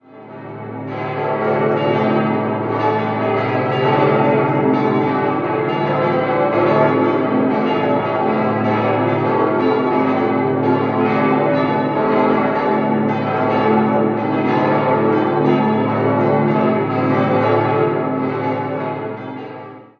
6-stimmiges Geläute: a°-c'-d'-e'-g'-a' Alle Glocken wurden von Karl Hamm in Regensburg gegossen: die drei kleineren schon 1947, die drei großen 1950. Es dürfte sich um das tontiefste noch vollständig erhaltene Geläut aus der Gießerei Hamm handeln.